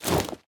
equip_wolf2.ogg